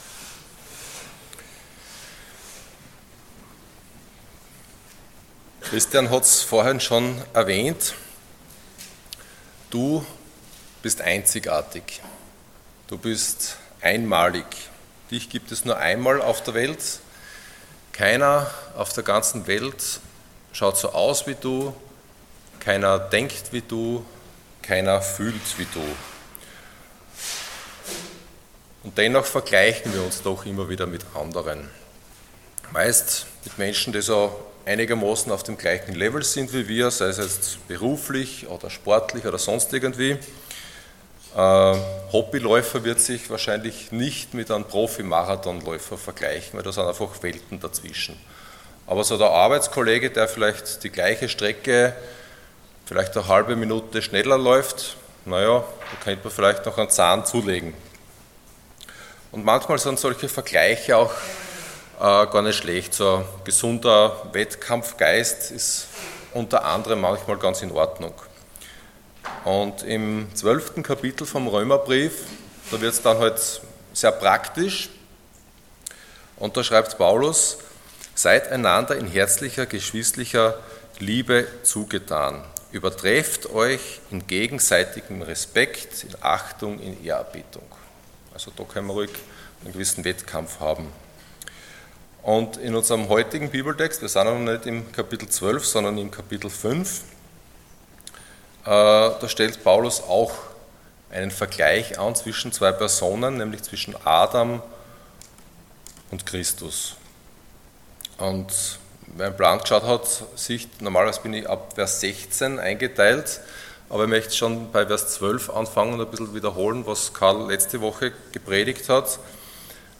Ihr werdet meine Zeugen sein Passage: Romans 5:12-21 Dienstart: Sonntag Morgen %todo_render% Adam